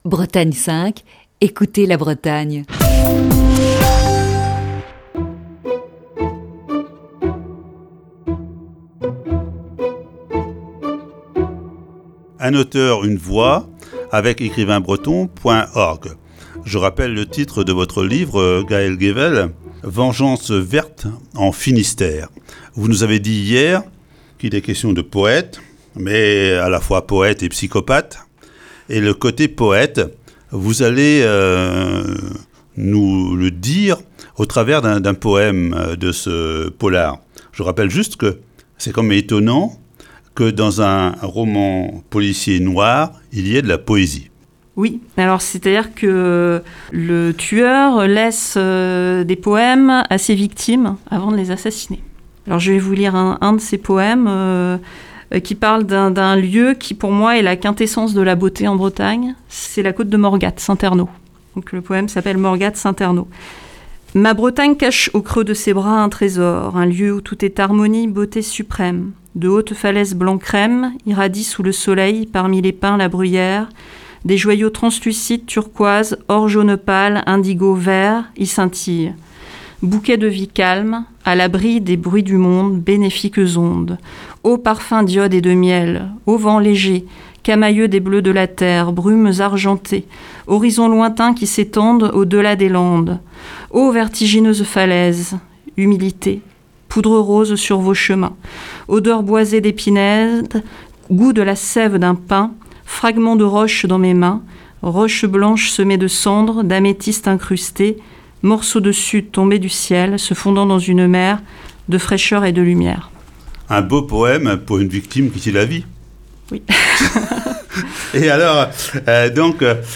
Il nous propose d'écouter ce vendredi la cinquième et dernière partie de cette série d'entretiens.